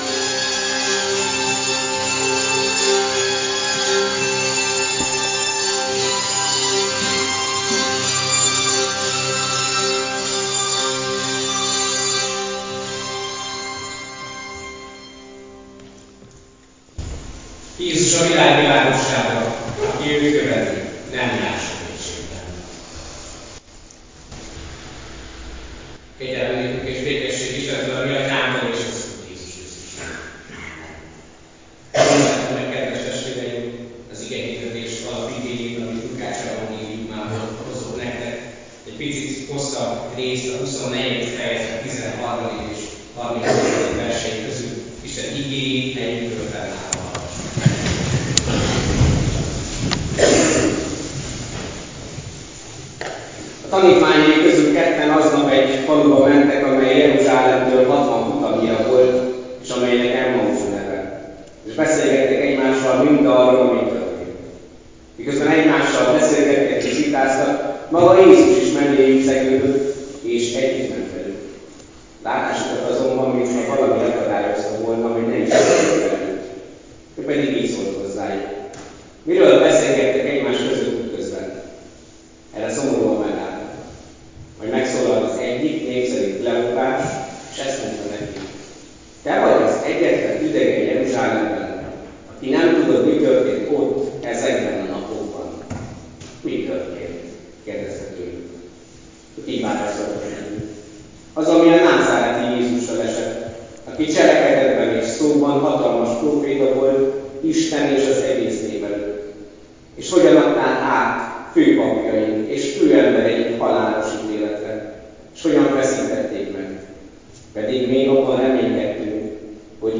HÚSVÉT HAJNALI ISTENTISZTELET - Kiváltom őket a holtak hazájából, megváltom őket a haláltól.